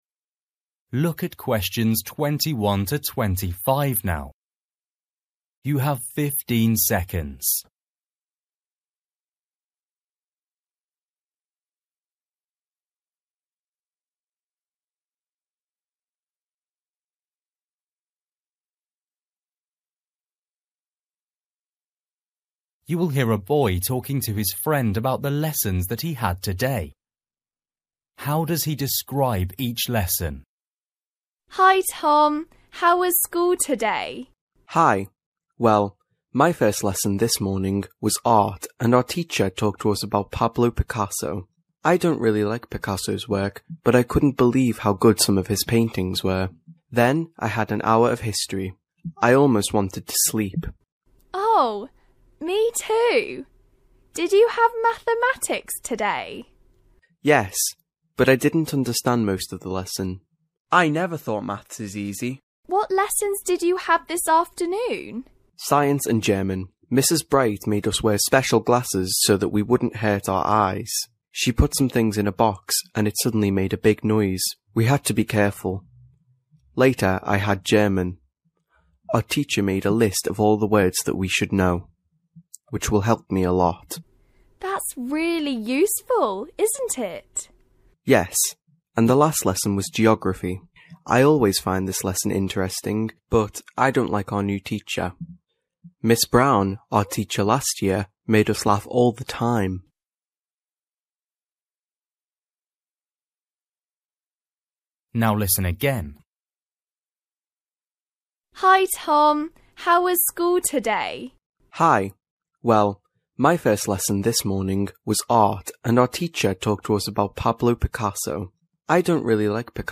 You will hear a boy talking to his friend about the lessons that he had today.